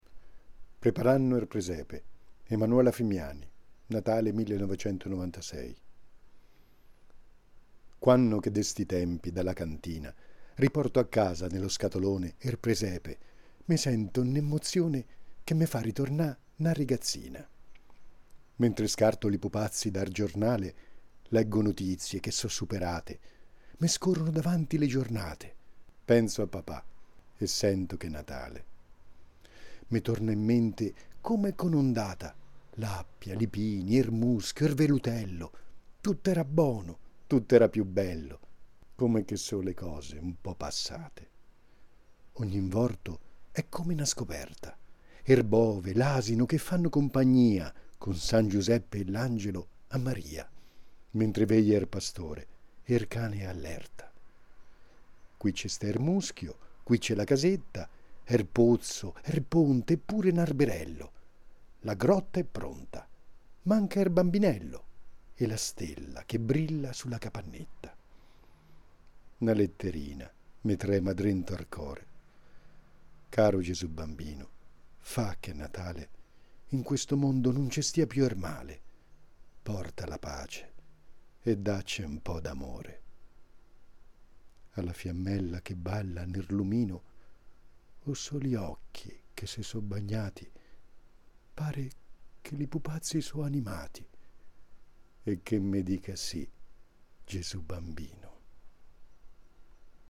Recitazione
Mi perdonino gli amici romani del mio saltuario avventurarmi nel dialetto romanesco.